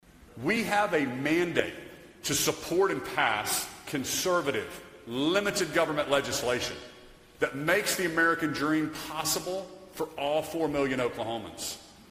Gov. Kevin Stitt gave his seventh State of the State address Monday at the State Capitol in Oklahoma City to kick off the 2025 legislative session.